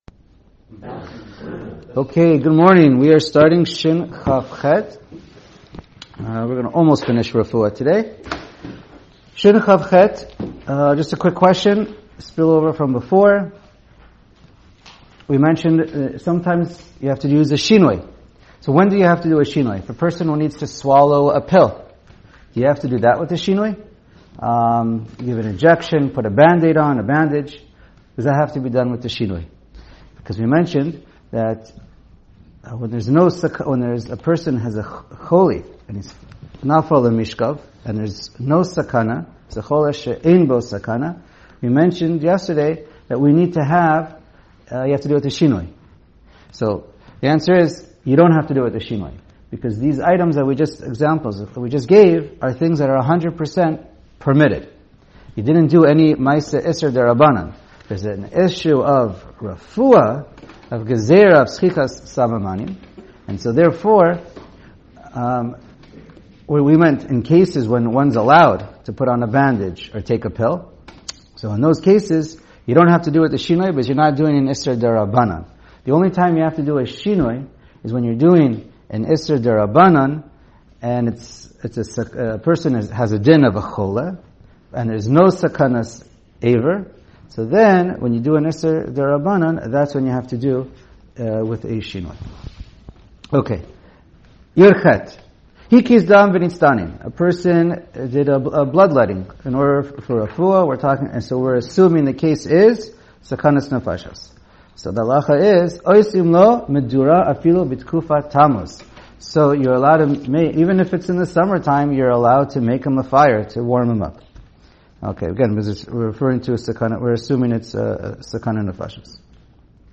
Shiur provided courtesy of Shulchan Aruch Yomi